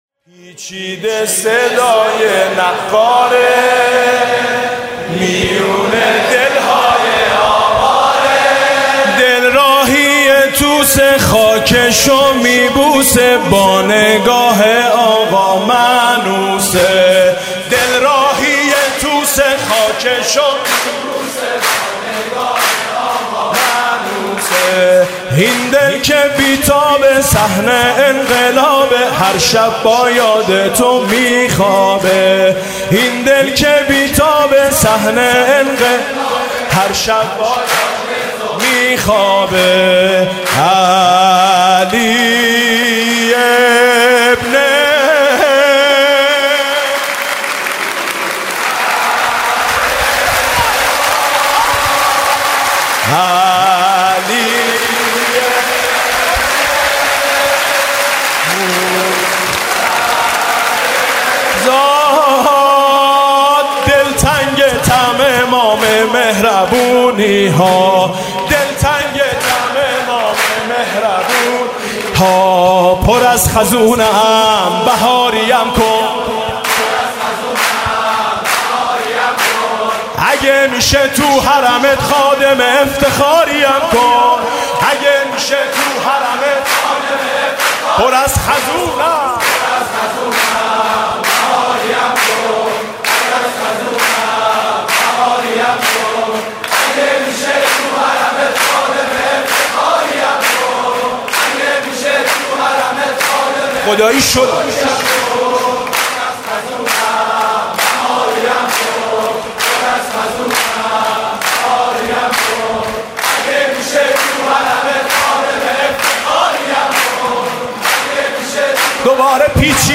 «میلاد امام رضا 1395» سرود: پیچیده صدای نقاره، میون دل های آواره